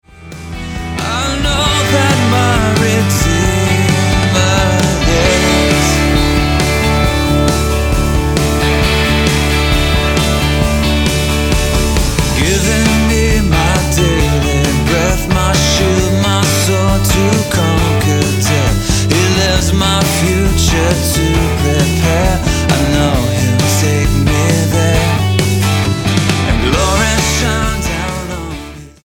Rock Album
Style: Pop Approach: Praise & Worship